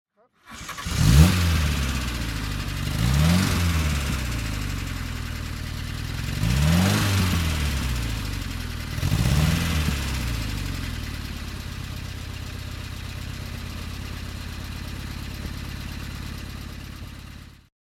Simca 1200 S (1970) - Starten und Leerlauf